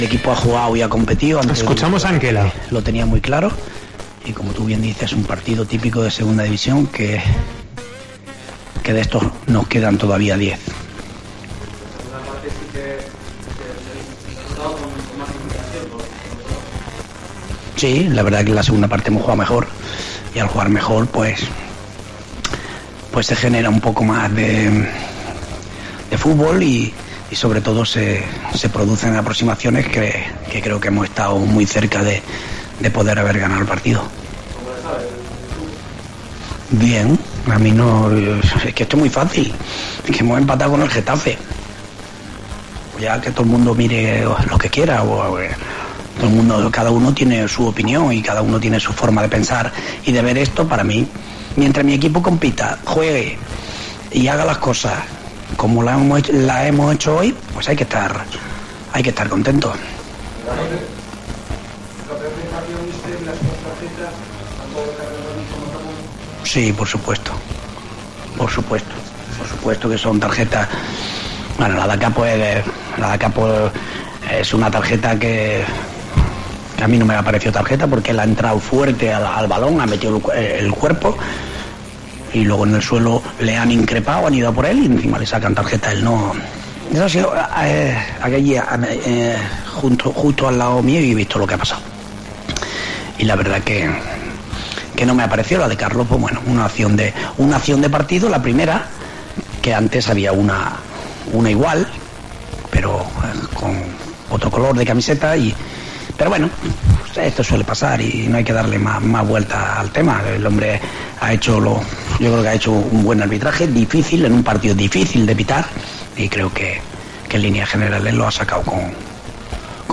Declaraciones del entrenador de la Sociedad Deportiva Huesca después de empatar (0-0) contra el Getafe.